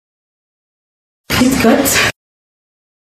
uitspraak Fritkot voorbeeld Normalement le mercredi je suis avec les enfants, on fait des activités créatives.